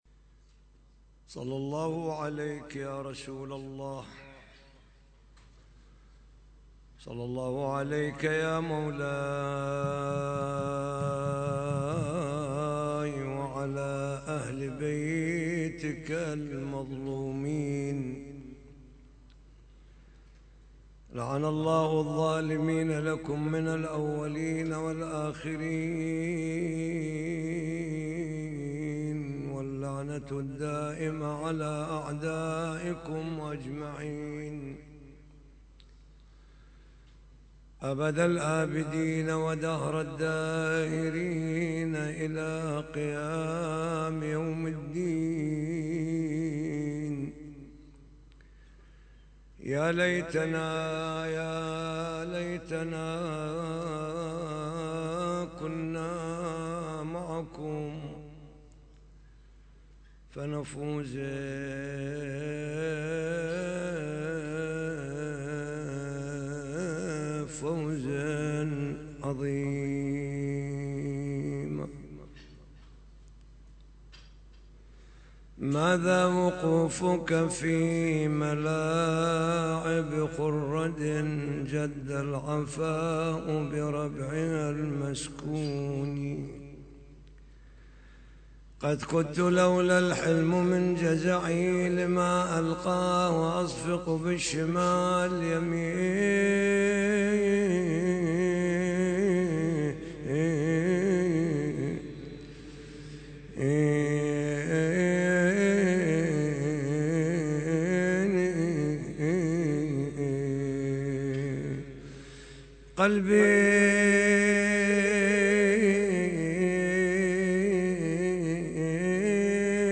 محاضرة ليلة 3 جمادى الثاني